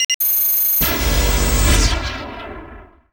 vehiclerelocate.wav